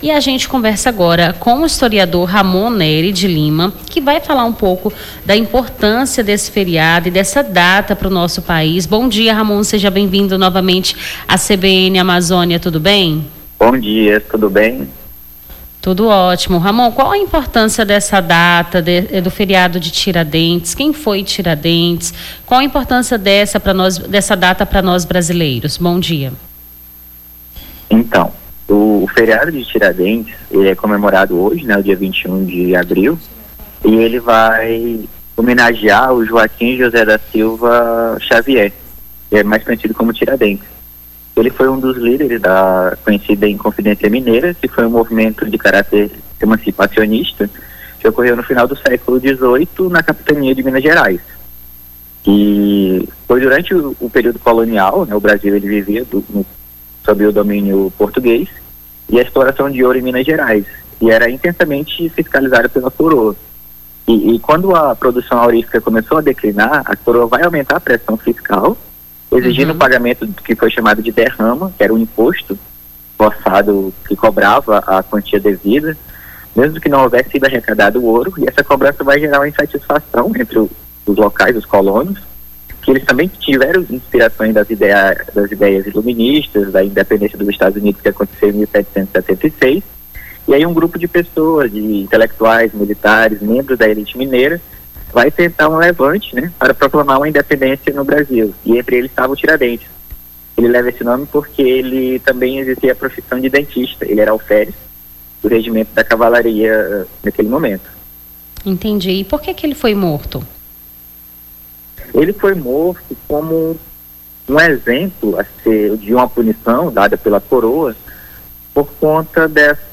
Nome do Artista - CENSURA - ENTREVISTA (HISTORIA DO TIRADENTES) 21-04-25.mp3